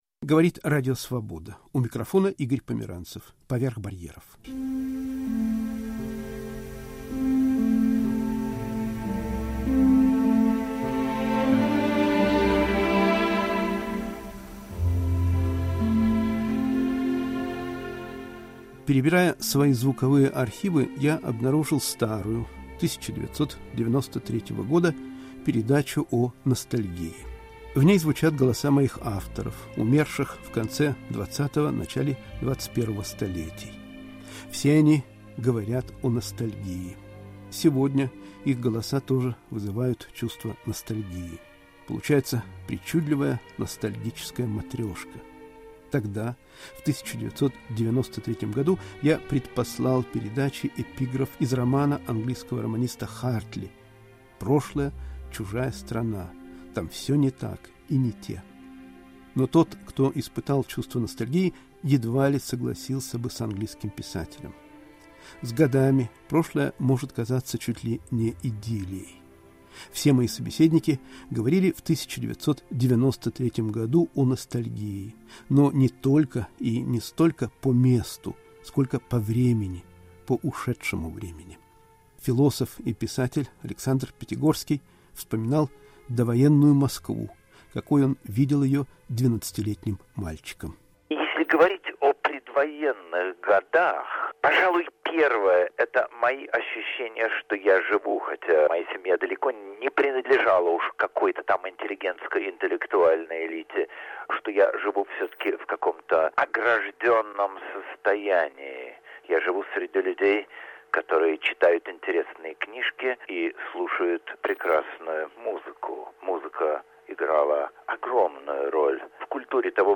Из радиоархива. Звучат голоса Александра Пятигорского, Булата Окуджавы, Асара Эппеля